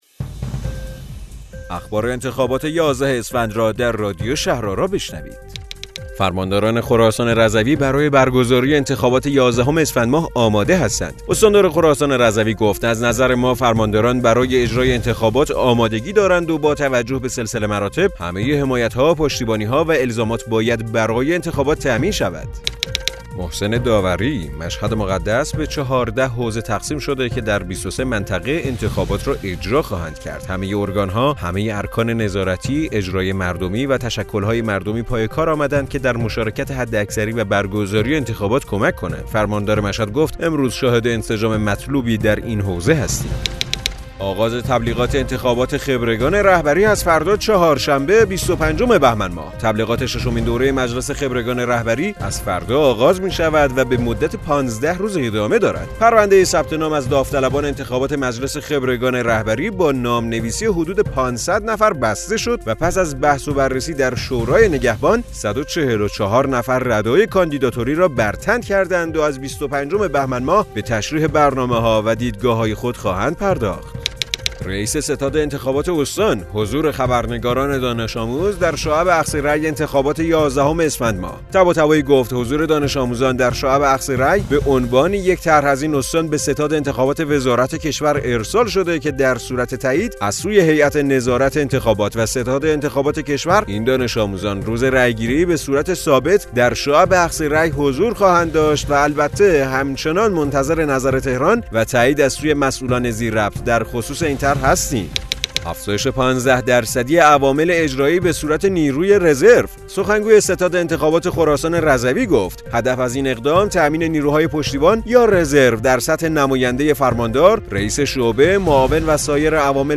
رادیو شهرآرا، پادکست خبری انتخابات یازدهم اسفندماه است.